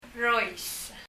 パラオ語 PALAUAN language study notes « year 年 ch sound chの音を聴く » mountain 山 rois [rɔis] 英) mountain 日） 山 Leave a Reply 返信をキャンセルする。